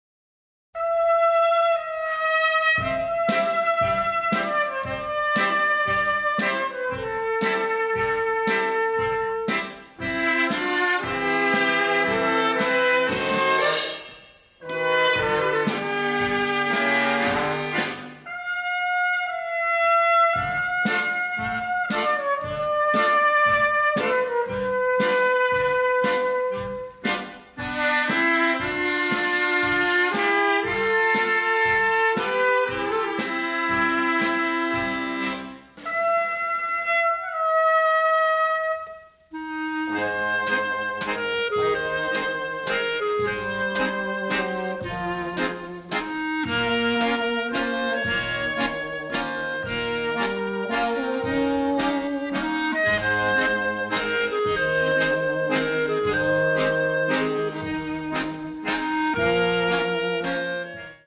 diegetic jazz and 1950s-styled dance tunes